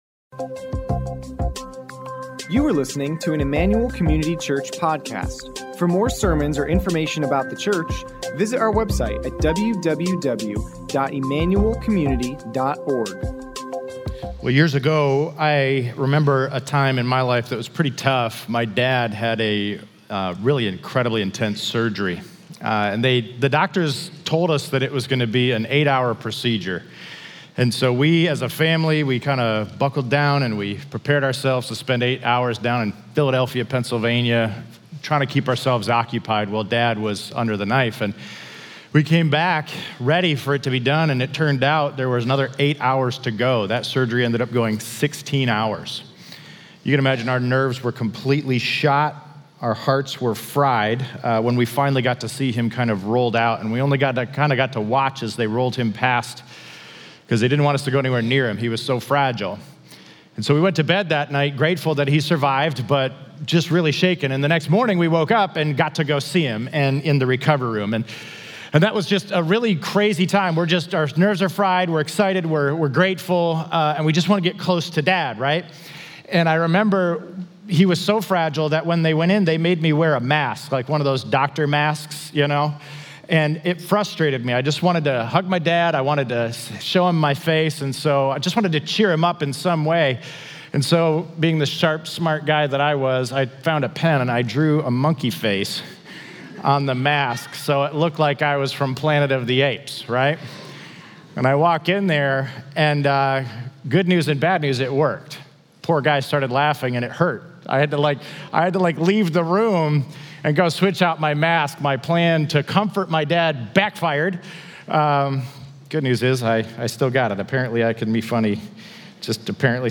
On Good Friday, we pause to remember the cross and what Jesus has done for us. This will be a reflective time of worship, Scripture, and Communion as we step into the weight and meaning of this day together.